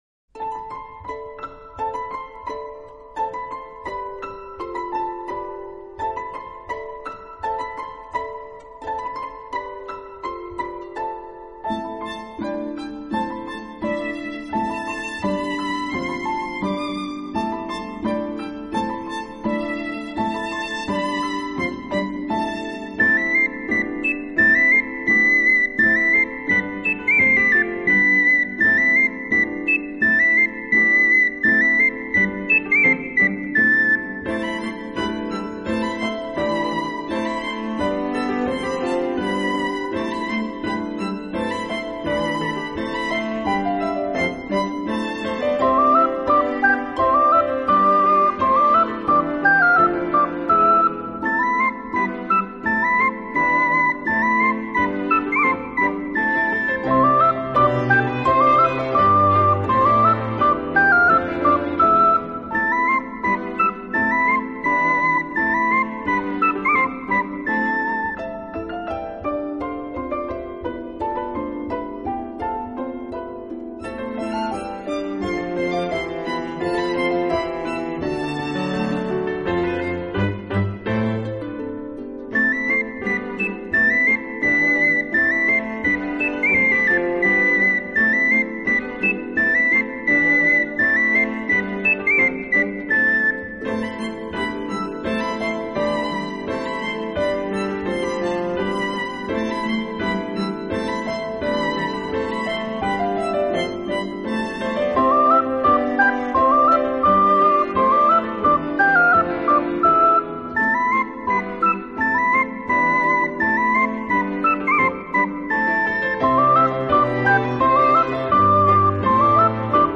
这张专辑是比较珍爱的陶笛专辑之一，感觉在这张专辑中陶笛不再是陶笛，
好多人都说女孩子天生爱做梦，其实这有什么不好呢？听着这梦幻般的陶笛，
却原来，依然是悠然的笛声，如清雨飘过，让你化作一颗颗透明的雨滴融入